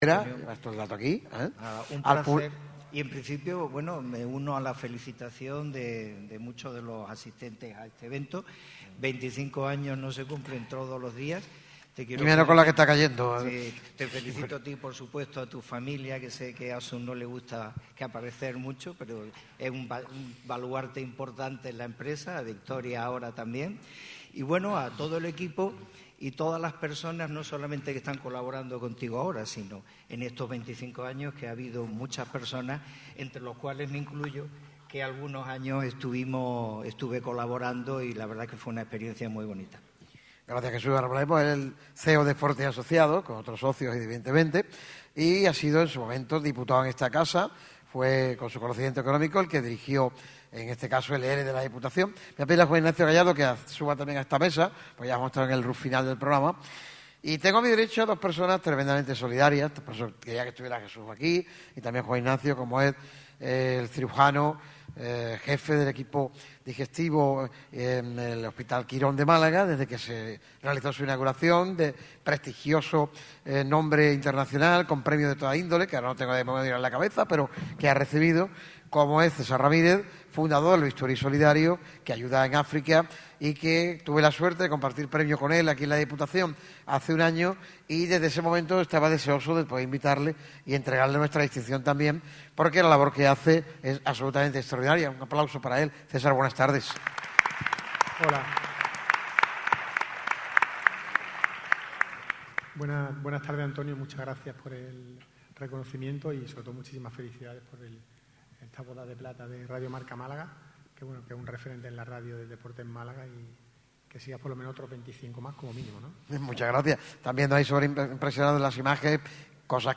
La emisora líder del deporte en Málaga está nuevamente de cumpleaños y no de uno cualquiera, sino de sus particulares bodas de plata. 25 años repartiendo información, debate, opinión y pasión con el deporte de la provincia costasoleña. Por ello, el micrófono rojo lo celebrará junto a numerosos invitados y protagonistas de excepción en el ya habitual y tradicional escenario del Auditorio Edgar Neville de la Diputación de Málaga.